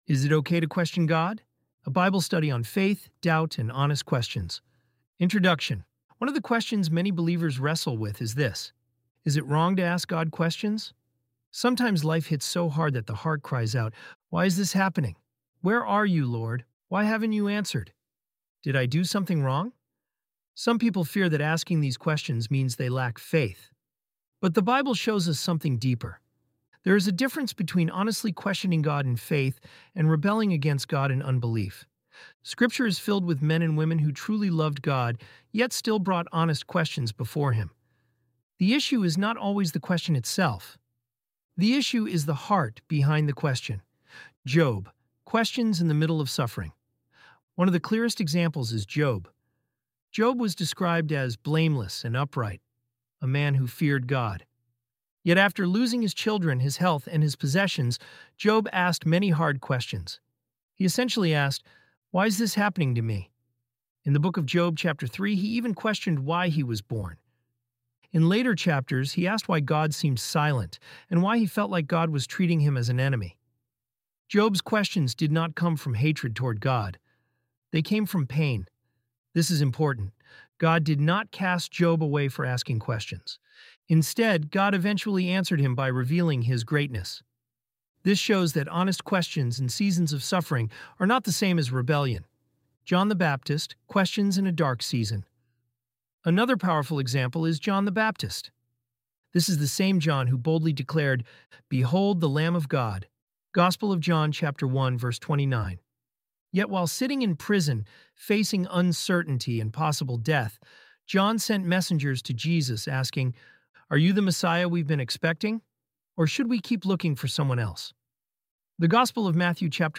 ElevenLabs_qg.mp3